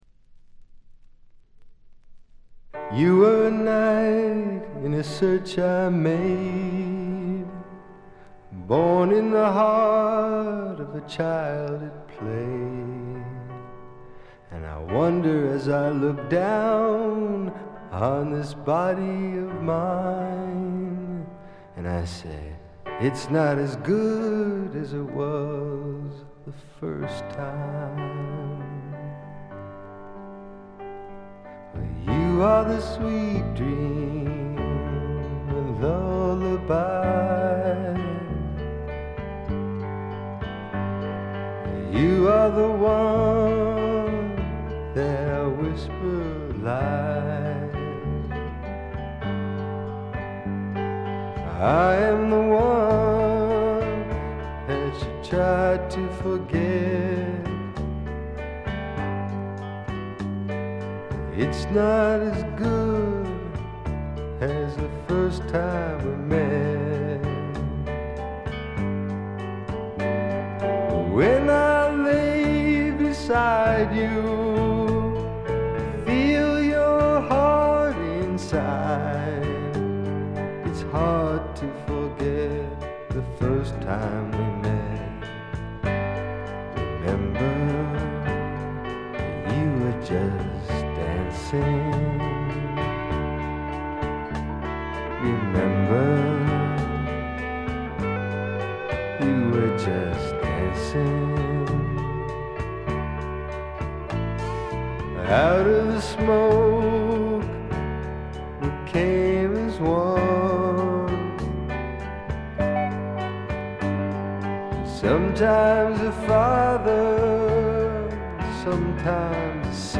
微細なチリプチがほんの少し。
試聴曲は現品からの取り込み音源です。
こちらもご覧ください　 レコード：米国 SSW / フォーク